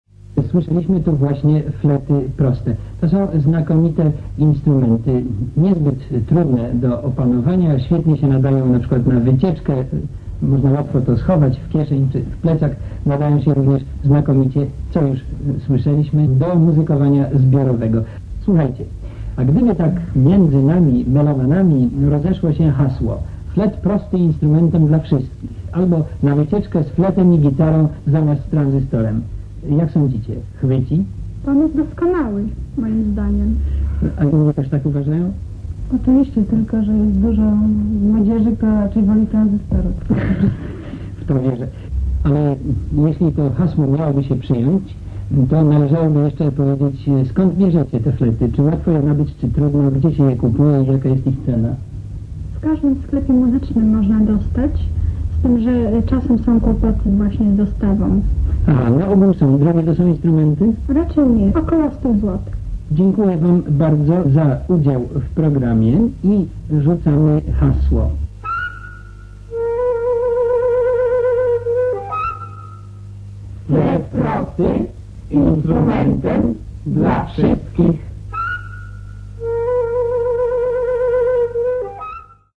Tylko tyle udało się jak na razie odnaleźć, a ich jakość, delikatnie mówiąc, nie jest najlepsza.
Audycja radiowa - część 2